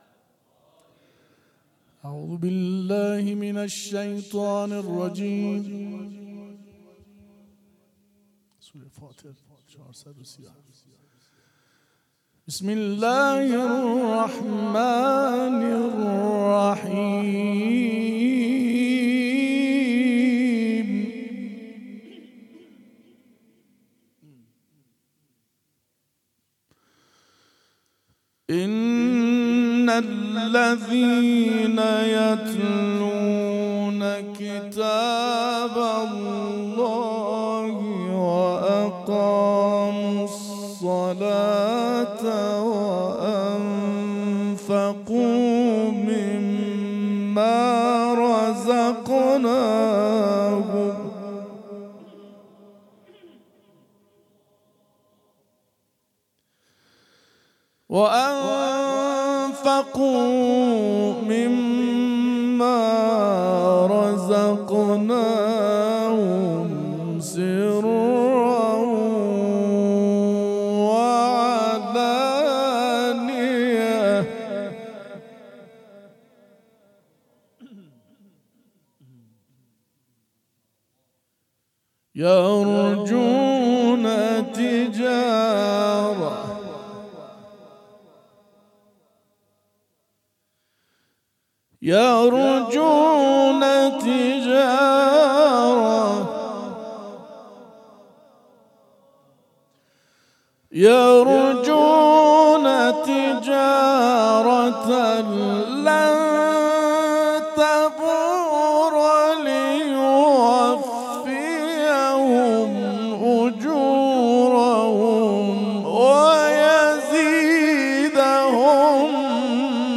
此次《古兰经》亲近会于上周日晚上7点在伊玛目萨迪克大学清真寺举行。